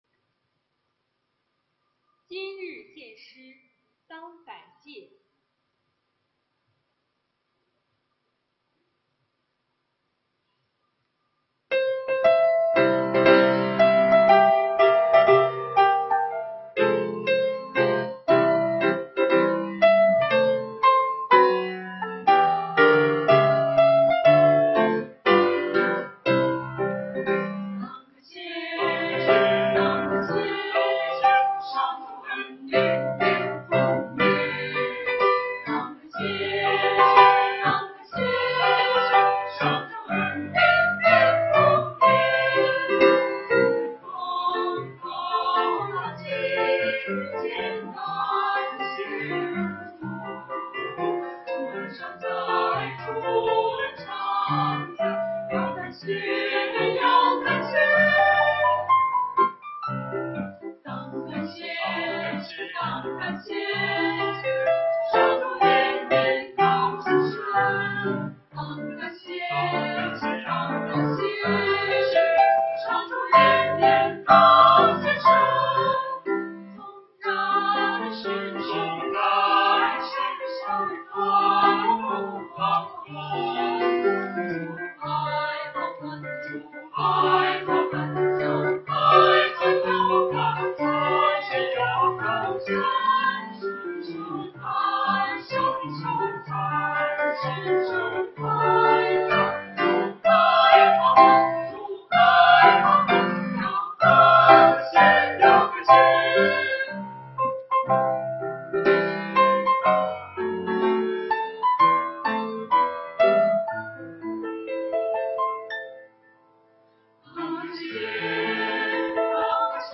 团契名称: 青年诗班 新闻分类: 诗班献诗 音频: 下载证道音频 (如果无法下载请右键点击链接选择"另存为") 视频: 下载此视频 (如果无法下载请右键点击链接选择"另存为")